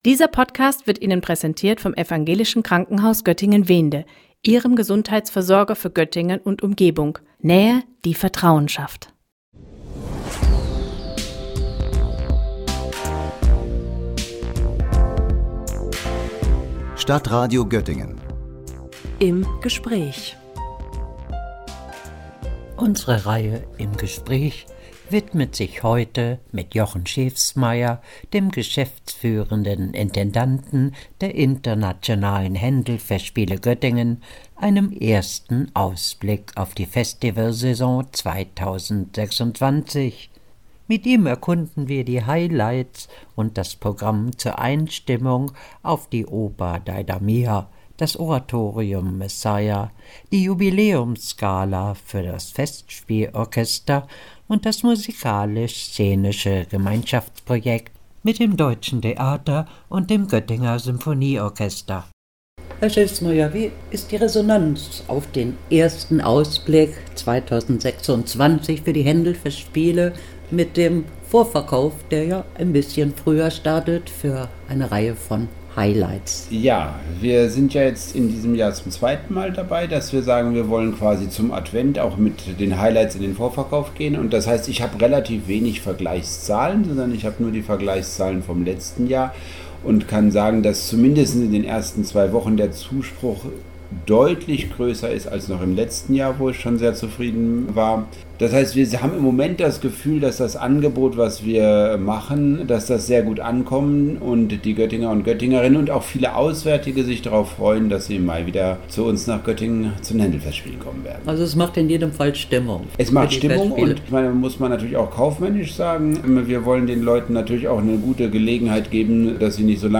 Ausblick auf die Internationalen Händelfestspiele Göttingen 2026 mit ersten Highlights – Gespräch